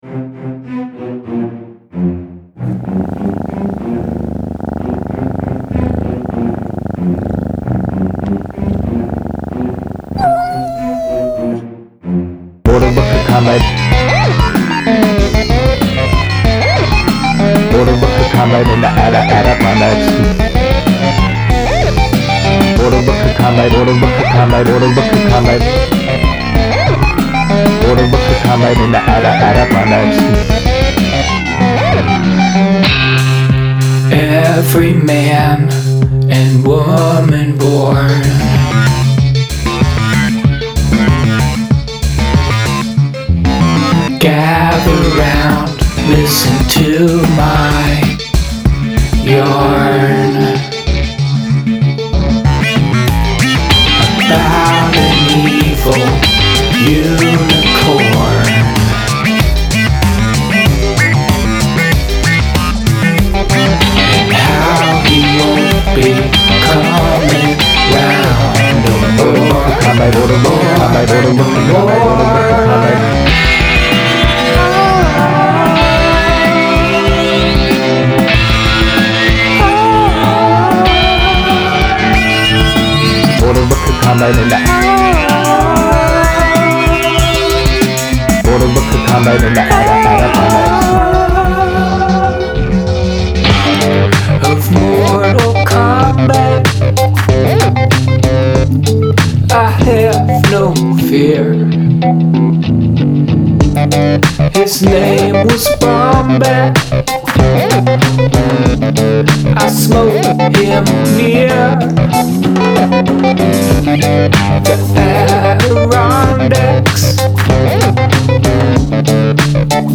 chorus, verse, chorus, middle part, chorus, verse, chorus
a silly song for a silly request. i think i let myself work on this one too long. it's too busy. i like some of synth sounds though. particularly in the middle section. i'm also trying my hand at some some vocal hooks. been listening to lady gaga recently.